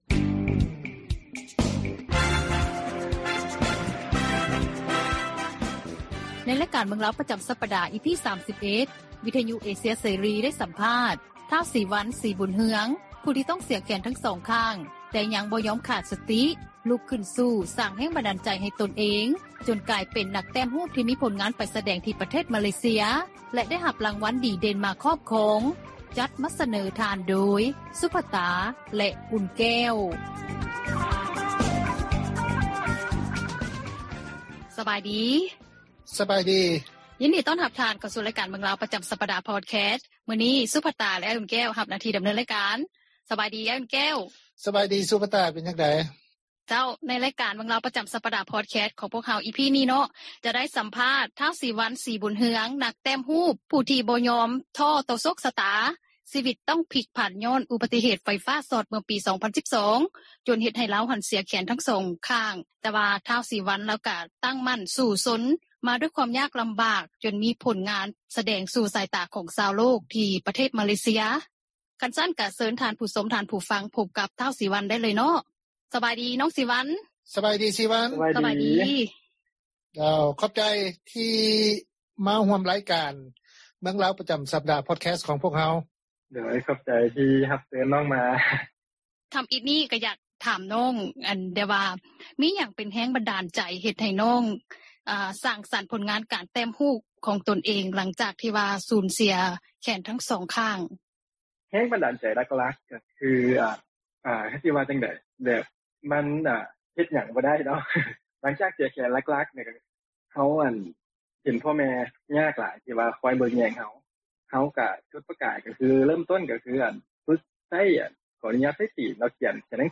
ສໍາພາດ